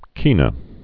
(kēnə)